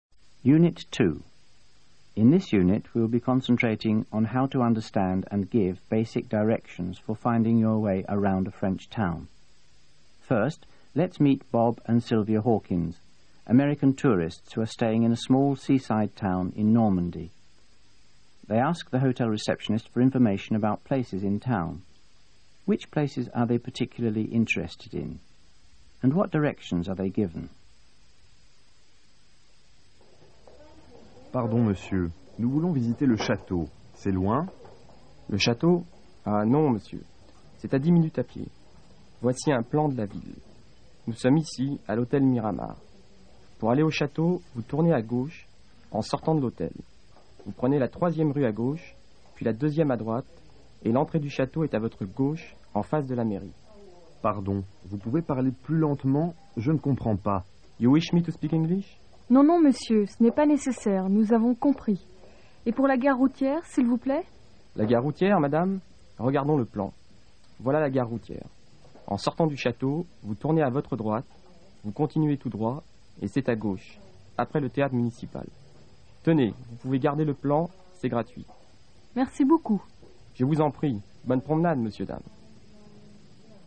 Both are recordings of people seeking directions.